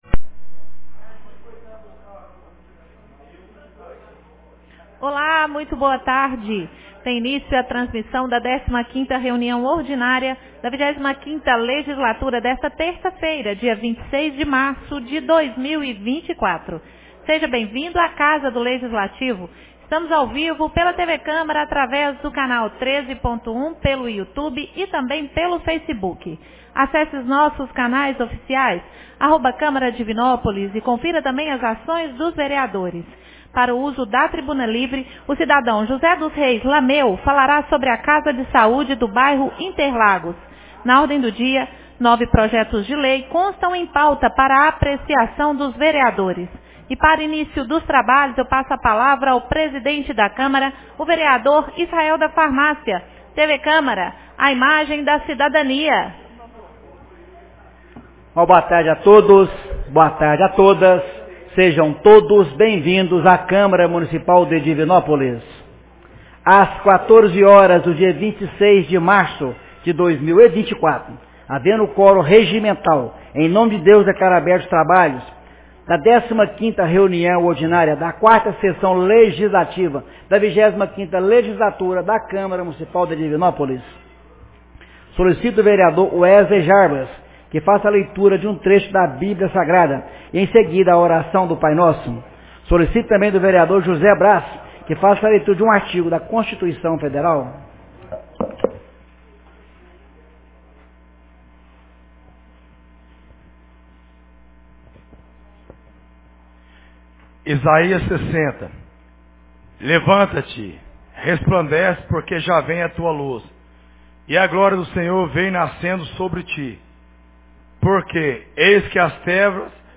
15ª Reunião Ordinária 26 de março de 2024